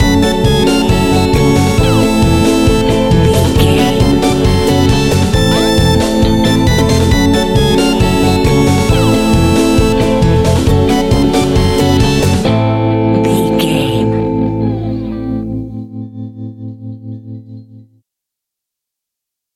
Aeolian/Minor
scary
ominous
eerie
piano
drums
bass guitar
synthesizer
spooky
horror music